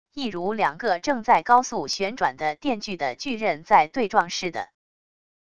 一如两个正在高速旋转的电锯的锯刃在对撞似的wav音频